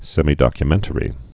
(sĕmē-dŏkyə-mĕntə-rē, sĕmī-)